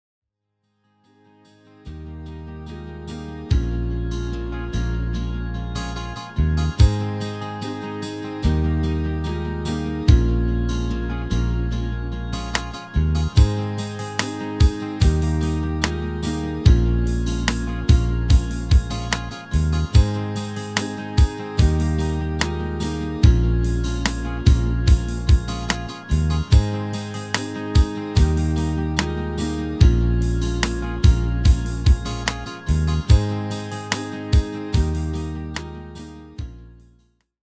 Extended MIDI File Euro 12.00
Demo's zijn eigen opnames van onze digitale arrangementen.